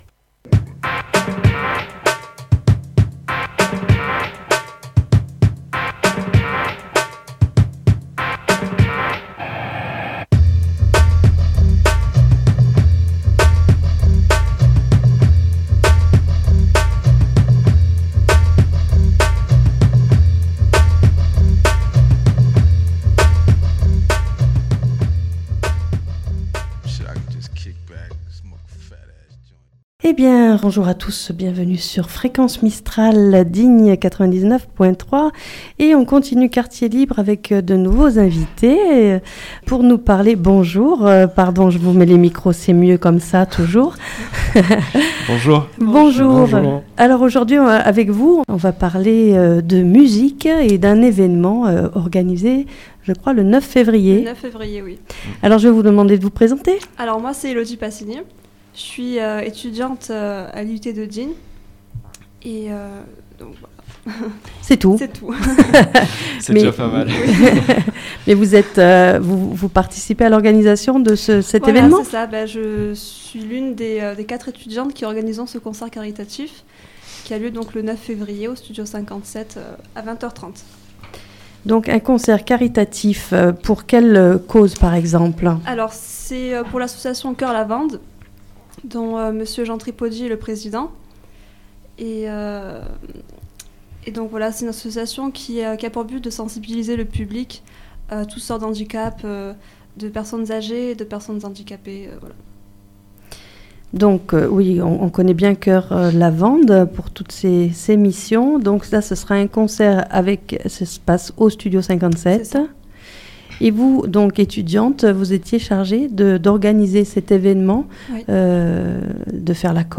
2 musiciens du groupe Too hot for Jane